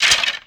Skeleton Attack Bone Rattle 3 Sound
horror